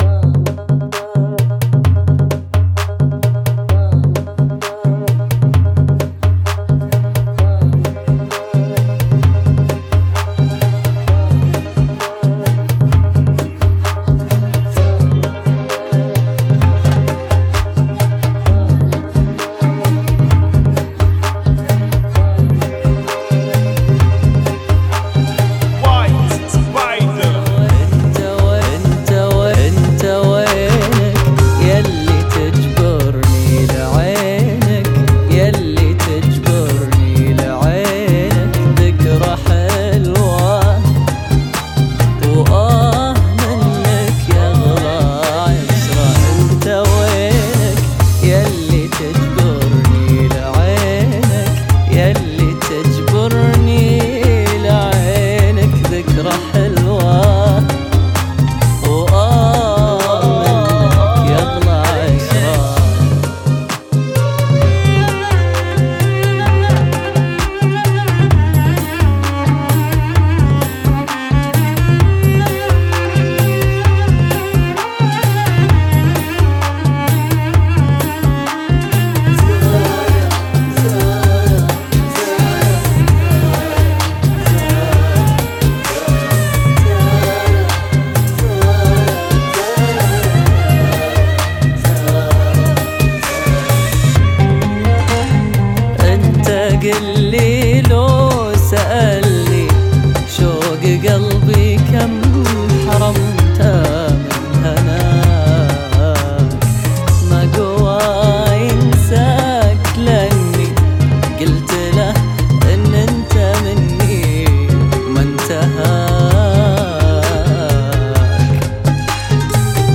Funky [ 65 Bpm